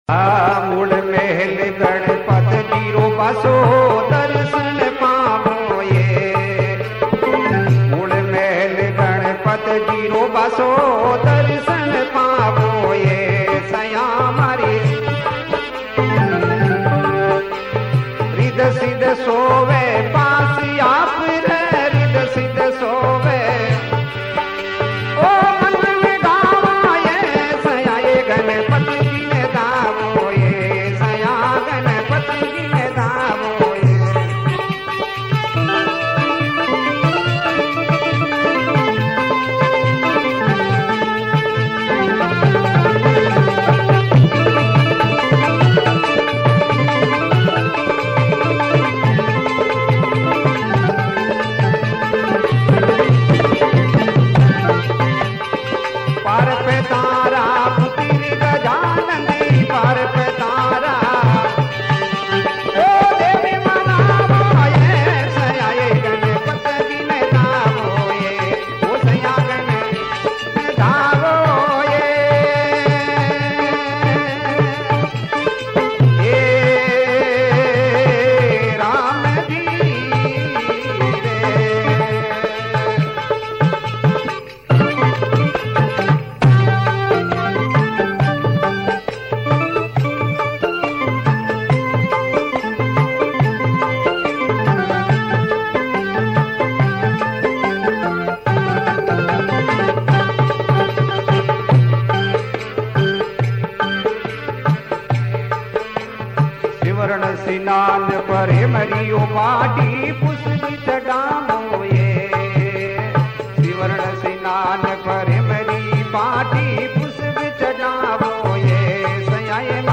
Rajasthani Songs
Satsang Bhajan